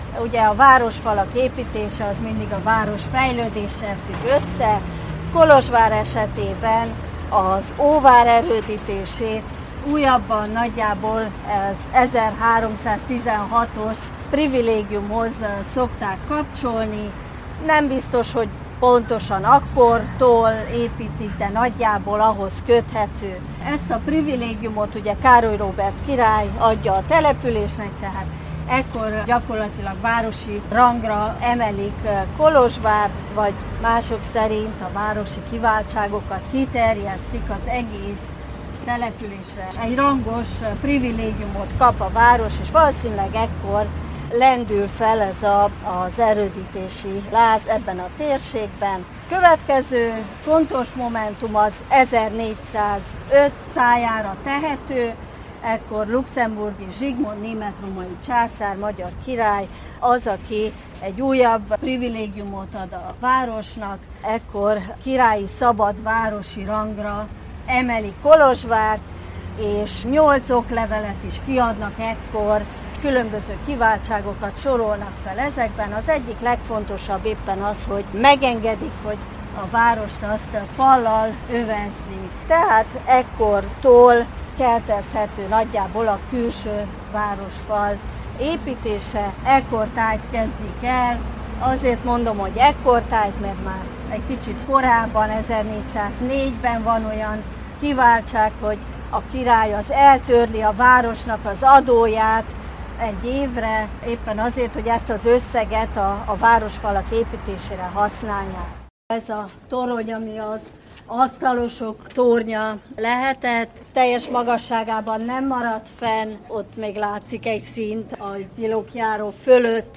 Idén három, különböző korokat érintő sétán vettünk részt, összeállításunkba ezekből válogattunk részleteket.
A városfalépítés történeti bevezetője után a Petőfi, ma Avram Iancu utcában az egykori Asztalosok tornya mellett időzünk el kicsit, majd a Fogoly utcai várfal mellett sétálunk, egy őrfülkére is feltekintve.